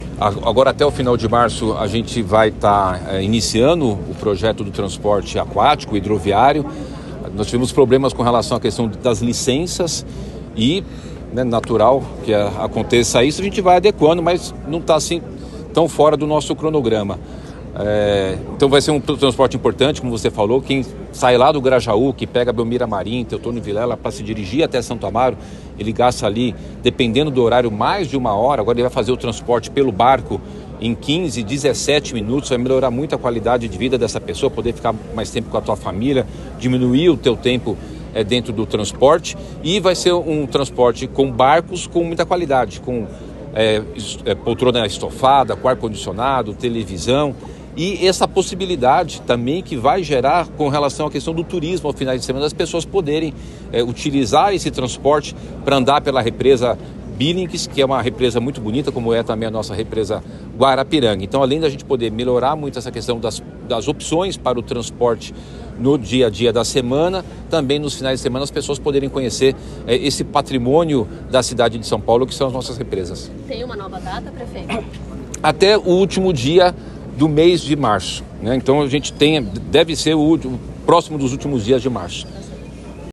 Em entrevista coletiva, Nunes disse que houve problemas em relação a licenças, mas que ainda não está “tão fora do prazo”.